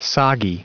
Prononciation du mot soggy en anglais (fichier audio)
Prononciation du mot : soggy